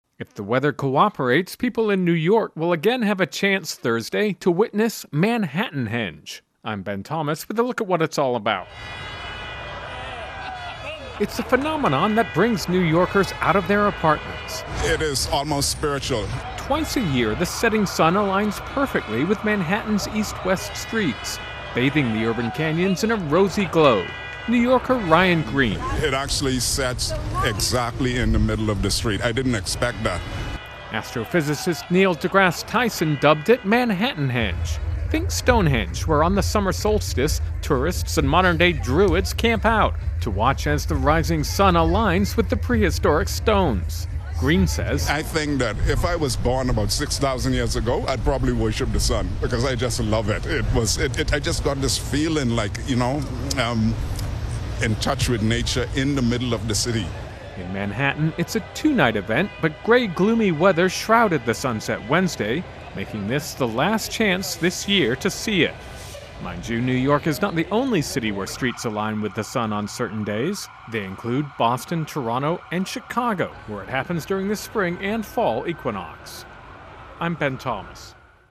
((NOTE: sound is from revelers from previous years))